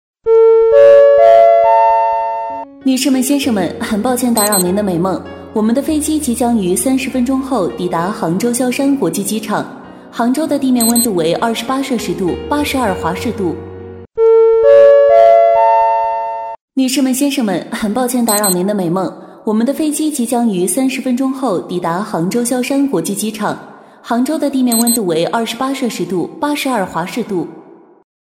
女26-机场广播-飞机上提示音
女26-机场广播-飞机上提示音.mp3